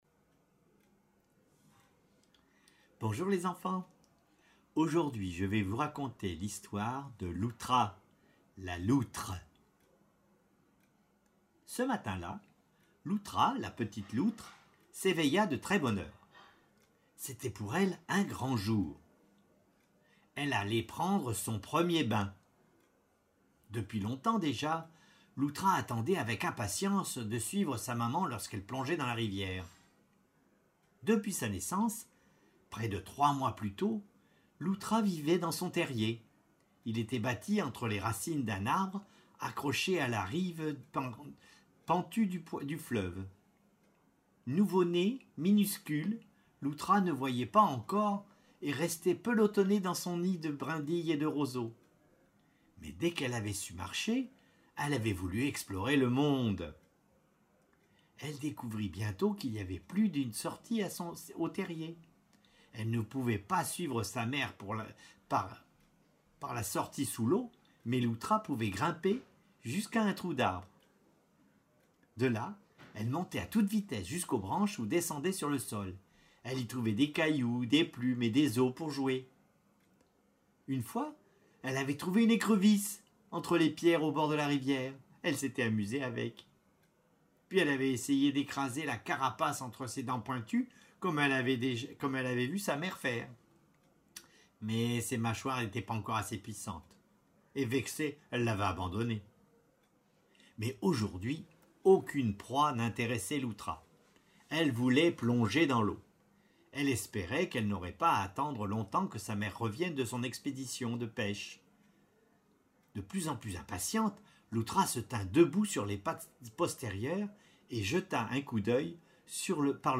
Une histoire